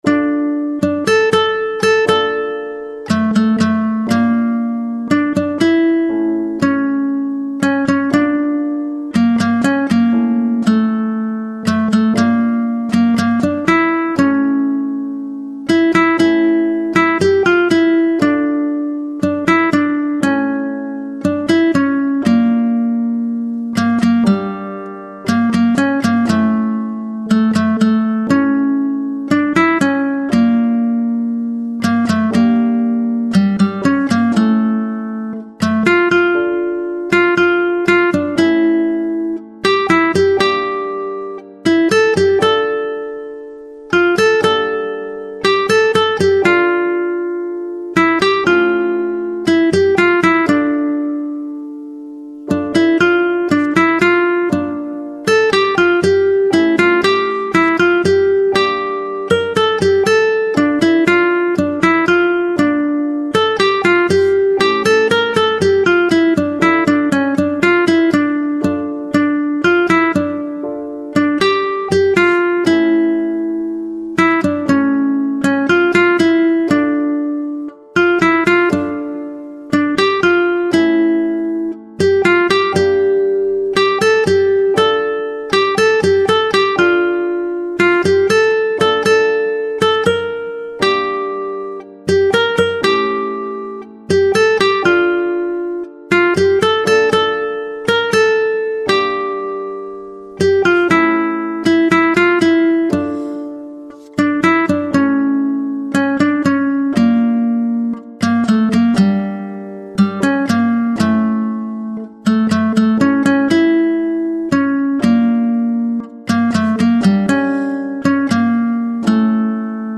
ساز : گیتار